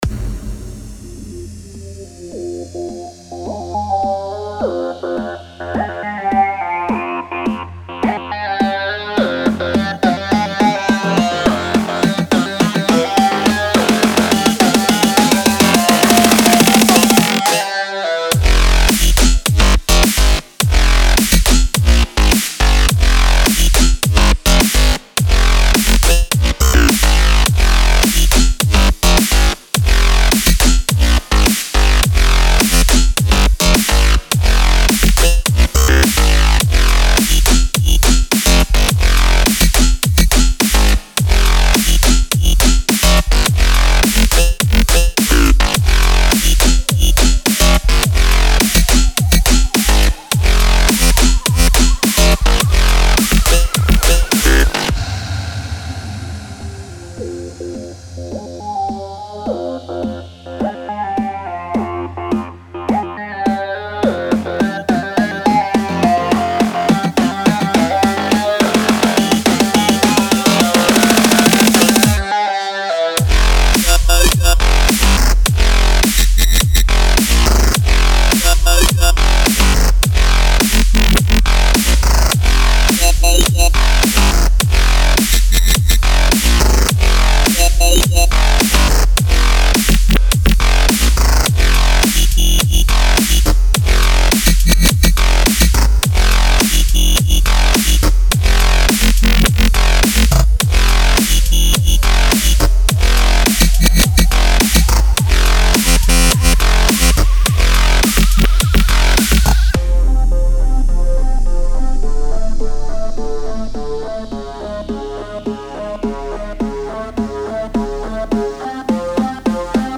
得到一些沉重的低音，黑暗的旋律和舞池砸鼓！